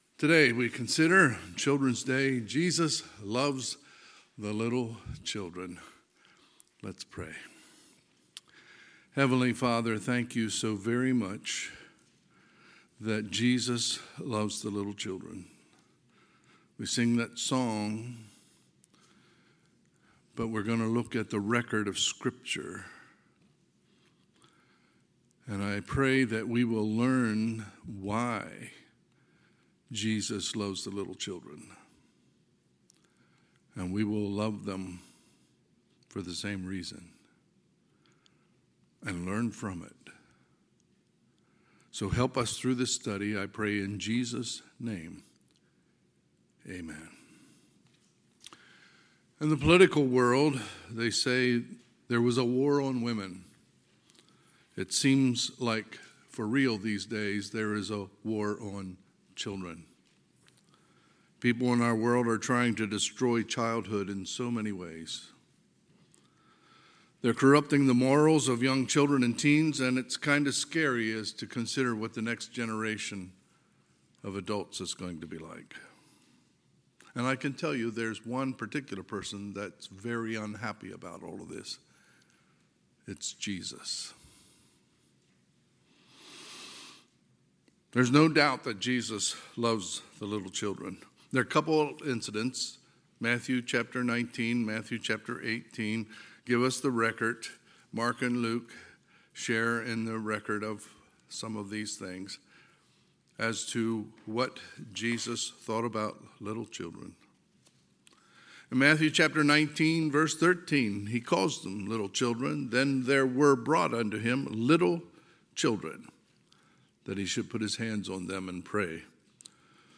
Sermons | North Hills Bible Church | Page 20